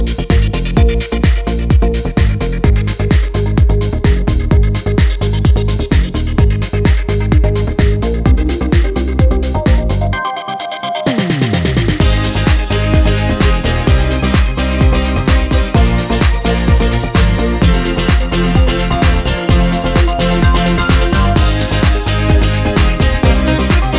no Backing Vocals Dance 3:48 Buy £1.50